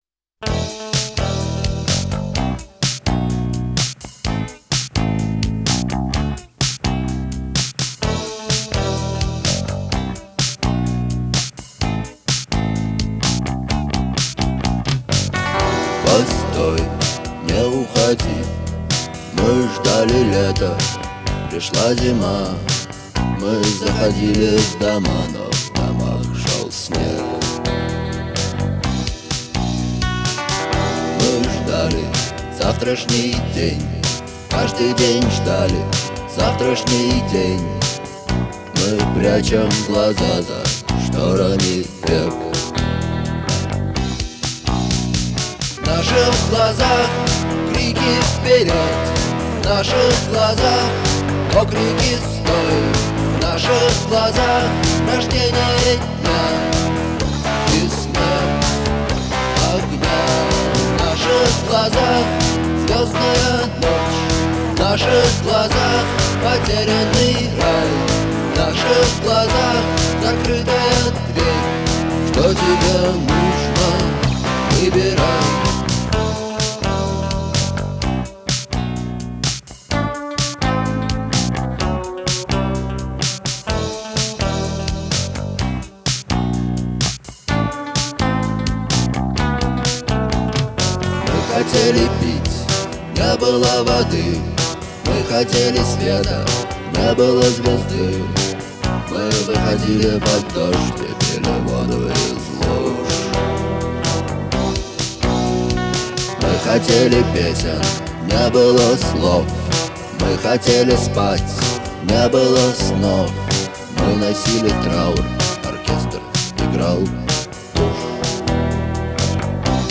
простые, но мощные гитарные рифы